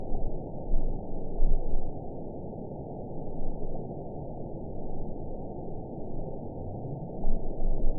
event 910670 date 01/27/22 time 16:24:42 GMT (3 years, 4 months ago) score 8.66 location TSS-AB07 detected by nrw target species NRW annotations +NRW Spectrogram: Frequency (kHz) vs. Time (s) audio not available .wav